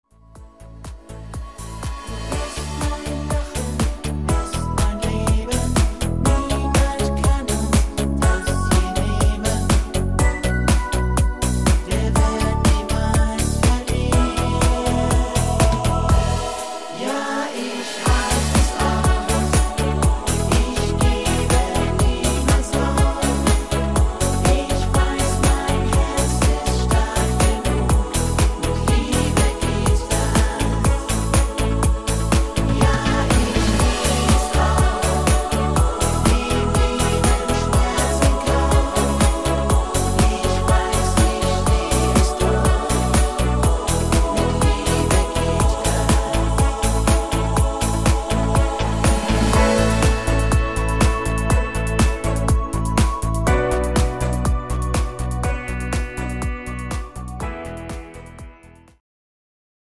Rhythmus  Party Discofox